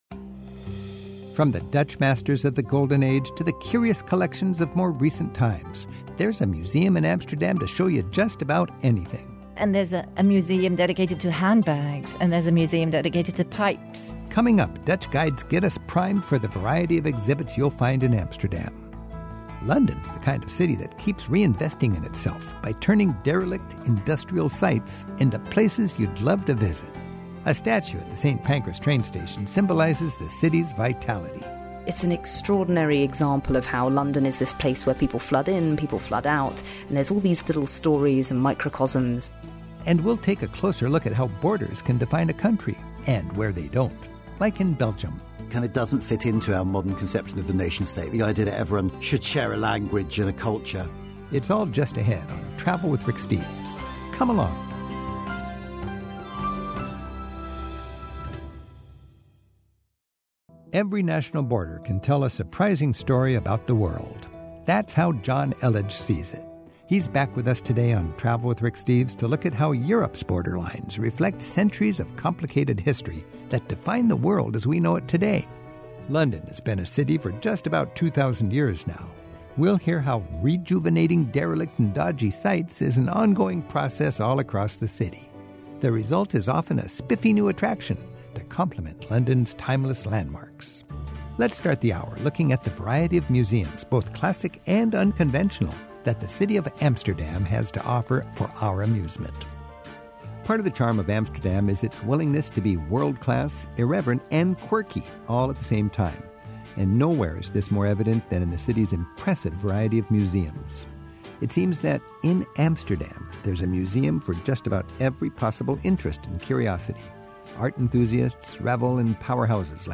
My Sentiment & Notes 818 Amsterdam Museums; Regenerating London; European Borders Podcast: Travel with Rick Steves Published On: Sat Jan 10 2026 Description: Two Dutch tour guides help us get the picture on what's new on Amsterdam's eclectic exhibit scene.